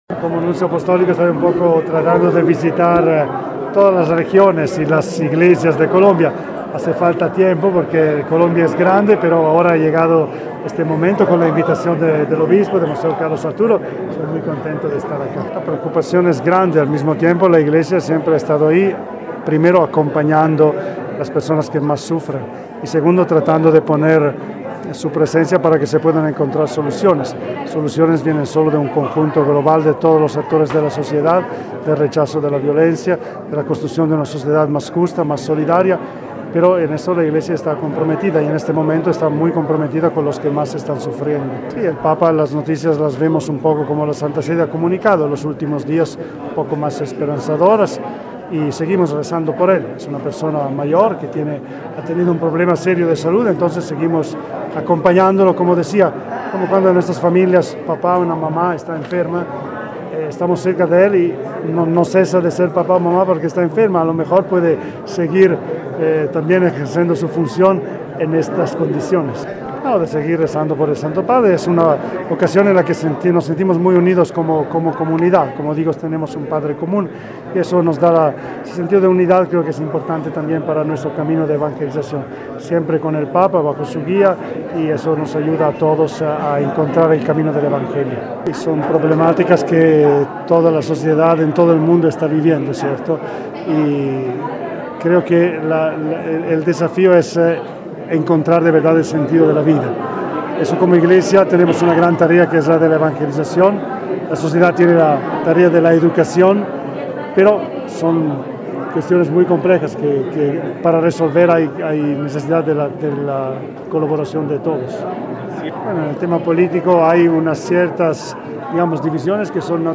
En su visita al Quindío, Monseñor Paolo Rudelli el Nuncio Apostólico en Colombia se refirió a la salud del papa